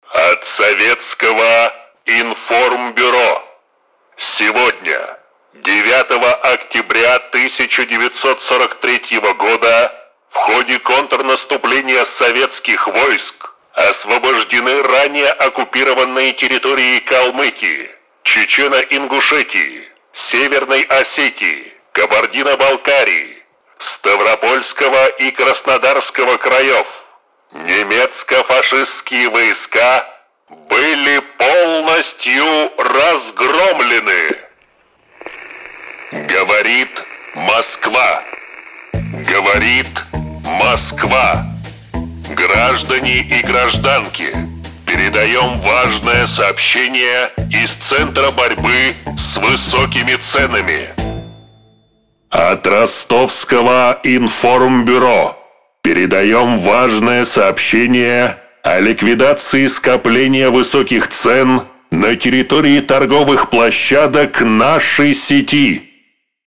Муж, Пародия(Левитан)
Звуковая карта: Auditnt id22 Микрофон: Neumann TLM 103 Преамп: Long voice master